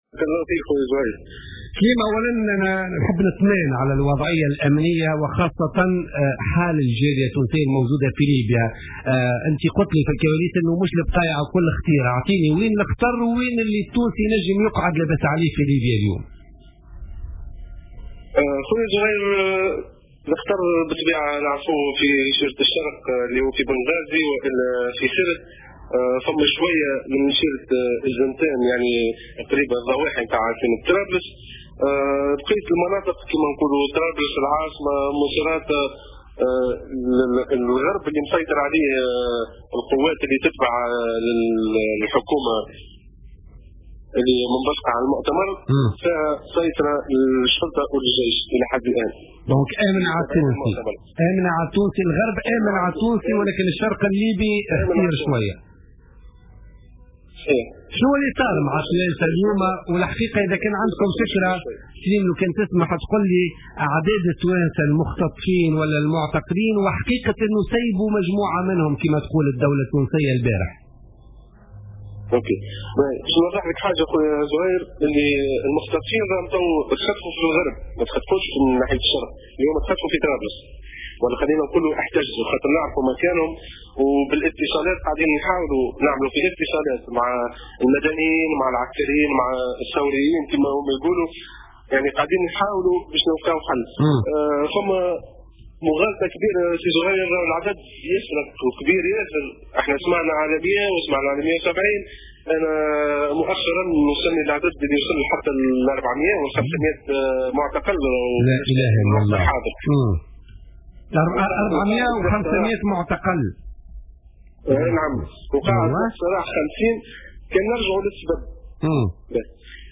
أكد صحفي تونسي قاطن بليبيا في تصريح لجوهرة أف أم اليوم الخميس 21 ماي 2015 في برنامج بوليتكا أن عدد التونسيين الذين اختطفوا في ليبيا يناهز 400 أو 500 تونسي.